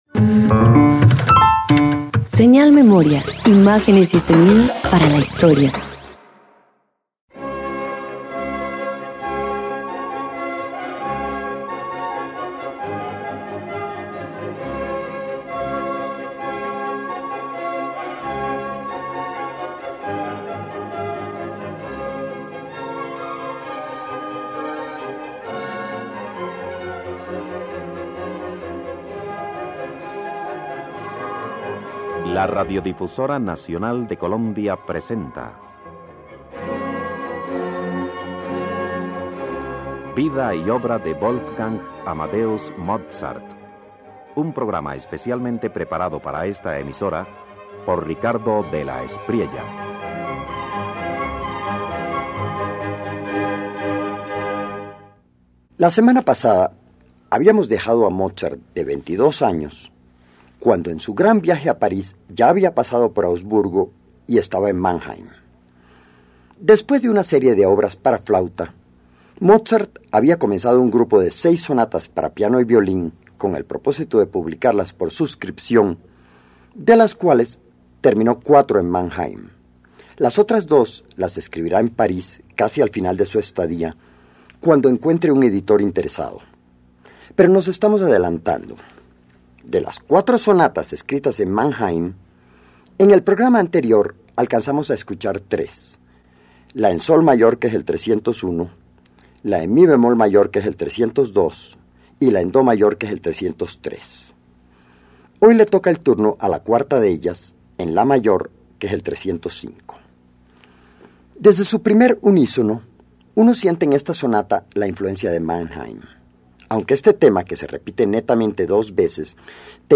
141 Música Instrumental compuesta antes de la llegada a Paris_1.mp3